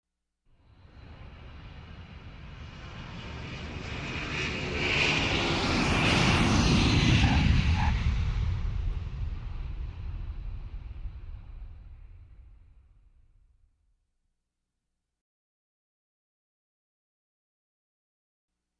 Реалистичные записи передают гул двигателей, скрип тормозов и другие детали, создавая эффект присутствия на взлетно-посадочной полосе.
Шум посадки самолета: кратко и важно